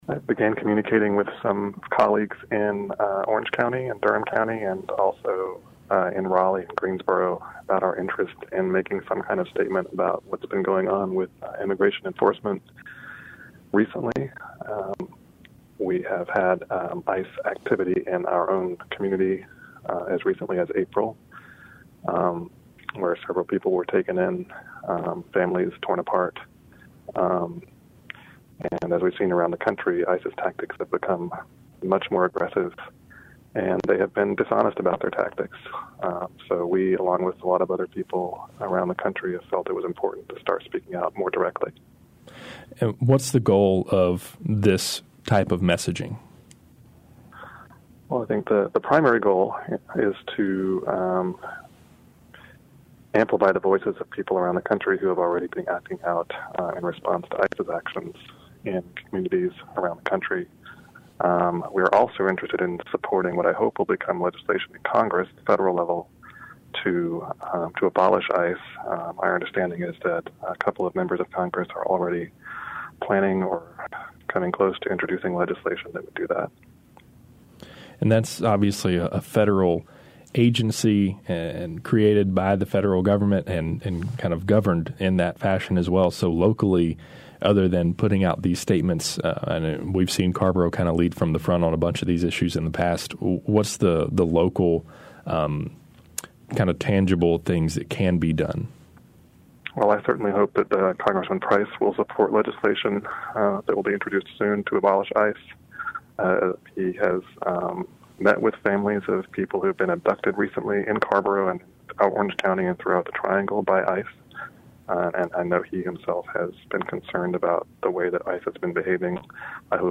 discussed the letter with Carrboro Alderman Damon Seils